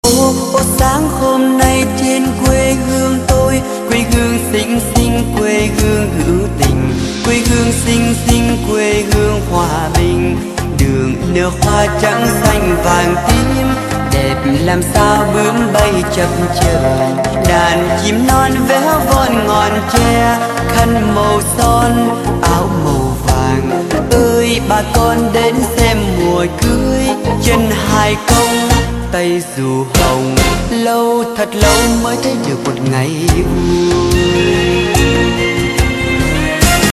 Nhạc Chuông Nhạc Vàng - Nhạc Đỏ